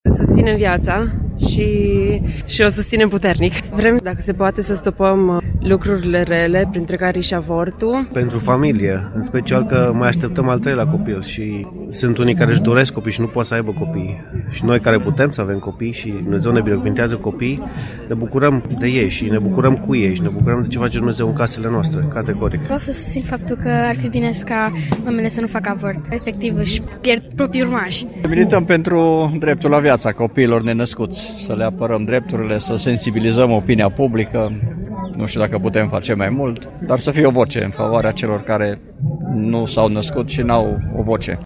voxuri-marsul-pentru-viaata-ok.mp3